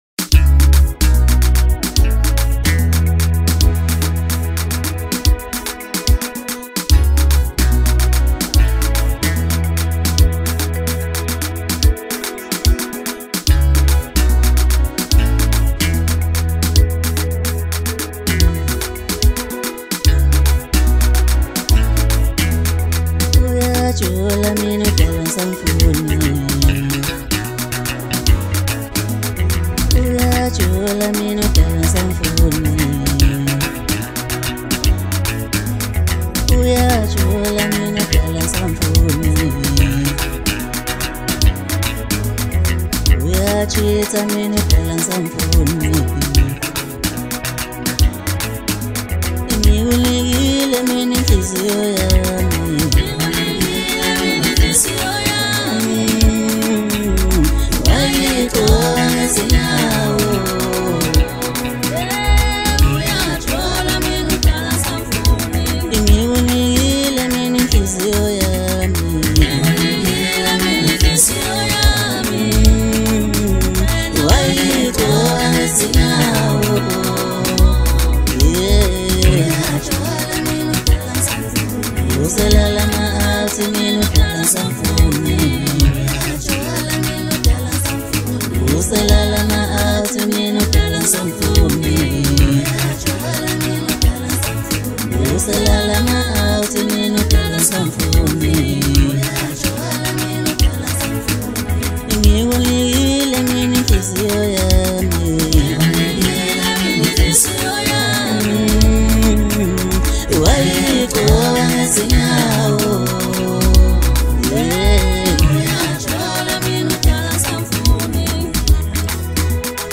Home » Maskandi
vibrant new song